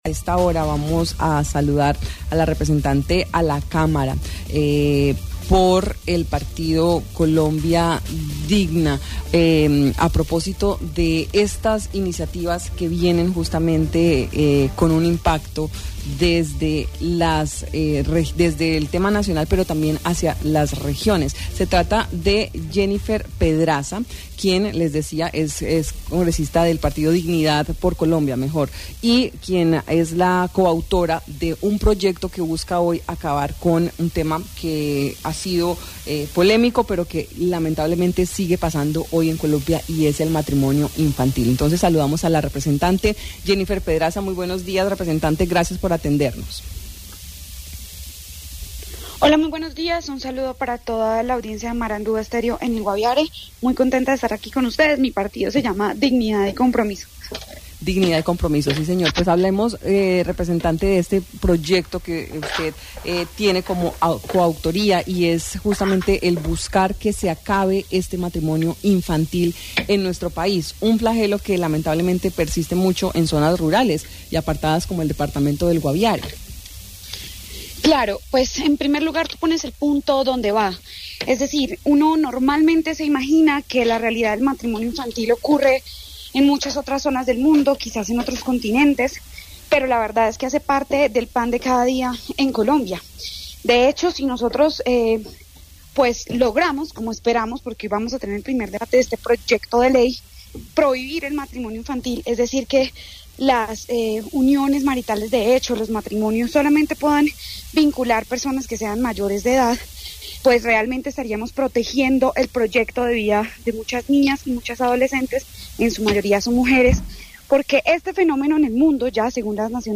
Así lo expresó Jennifer Pedraza, representante a la Cámara del partido Dignidad y Compromiso, en Marandua Noticias, al presentar una iniciativa que busca prohibir el matrimonio infantil en Colombia.